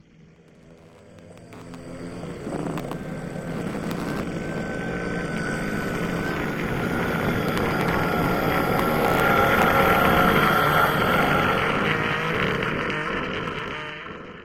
Minecraft / mob / endermen / stare.ogg
should be correct audio levels.